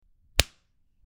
Punch 05
Punch_05.mp3